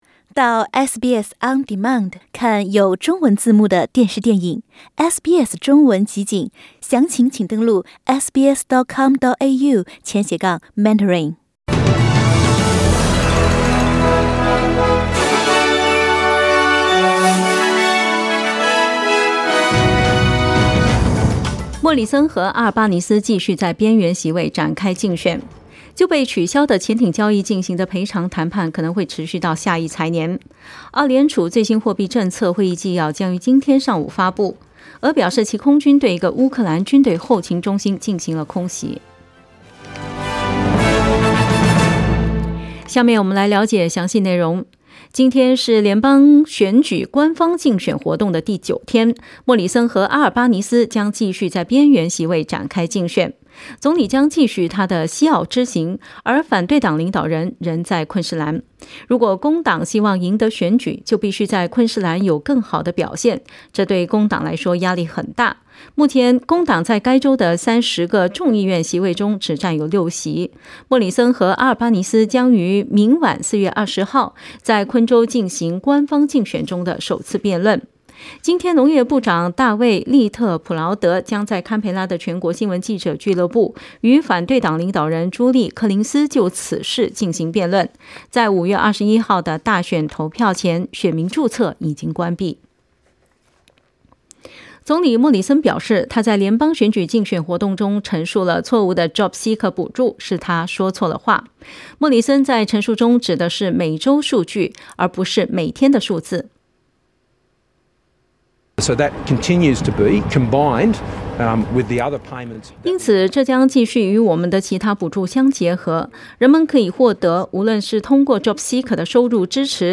SBS早新闻（4月19日）